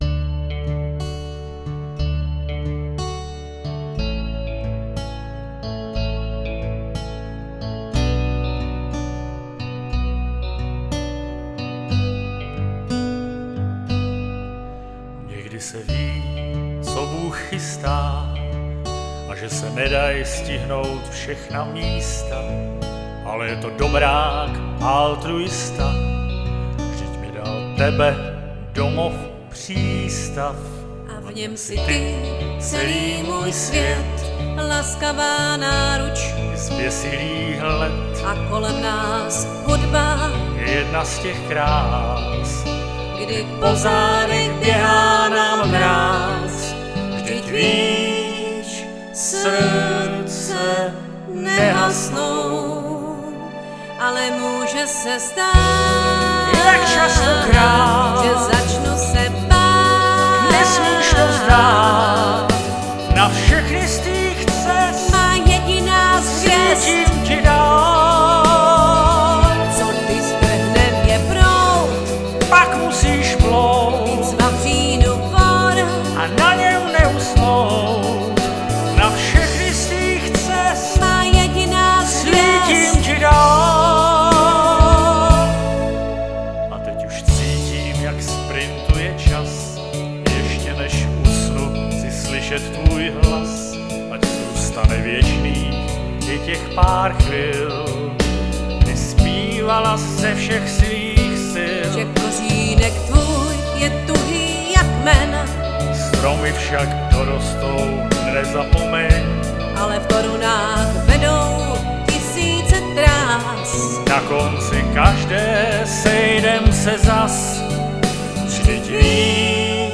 UKÁZKY SE ZPĚVÁKEM V TRIU